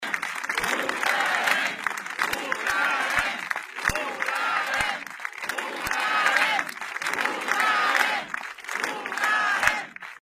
A Calonge